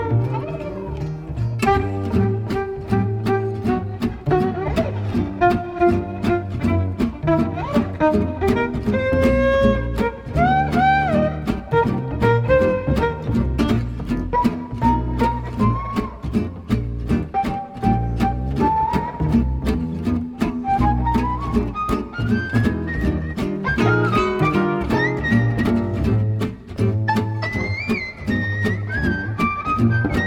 "frPreferredTerm" => "Jazz"